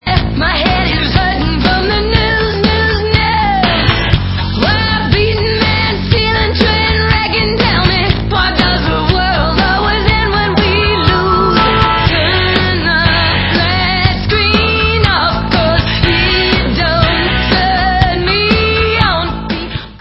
sledovat novinky v oddělení Rock